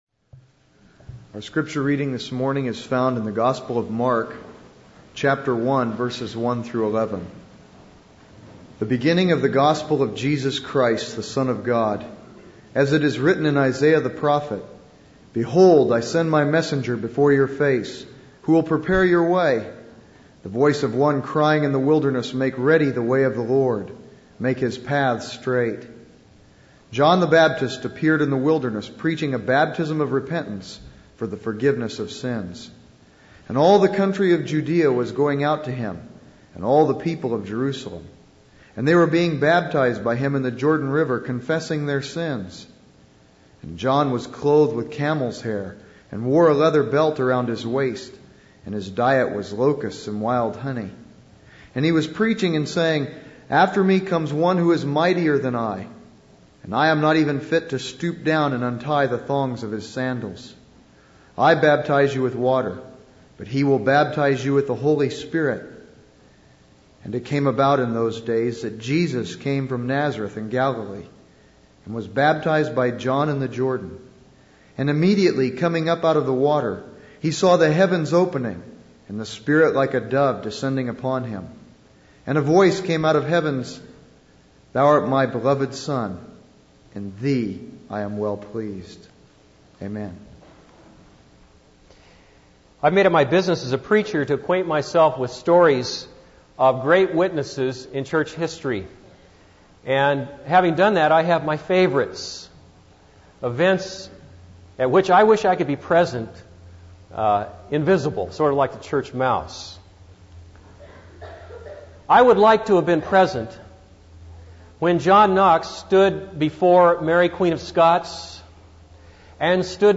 This is a sermon on Mark 1:1-11.